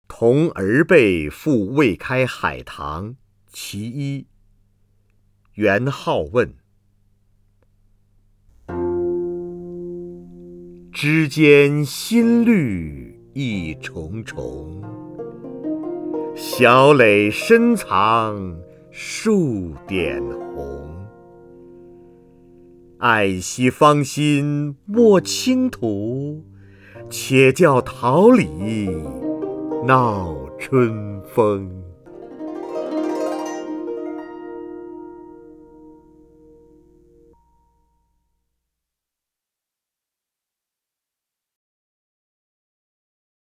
瞿弦和朗诵：《同儿辈赋未开海棠》(（金）元好问)
名家朗诵欣赏 瞿弦和 目录